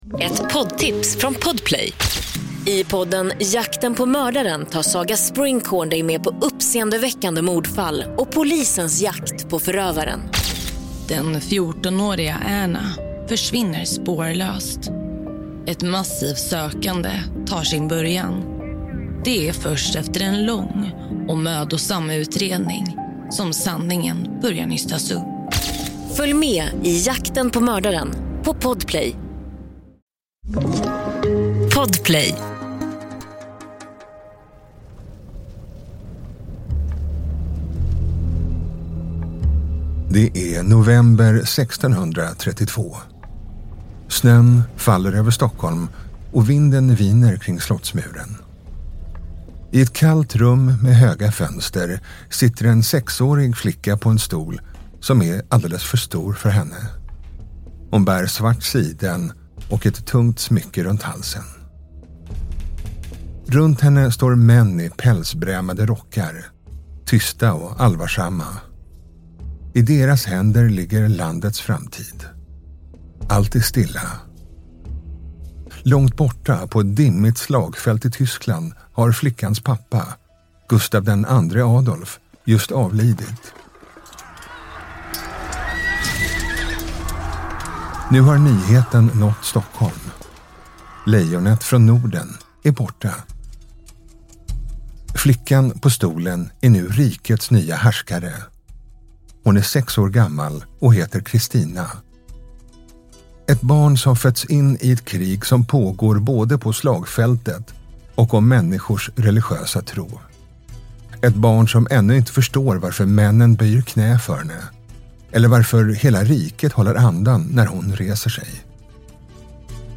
Berättarröst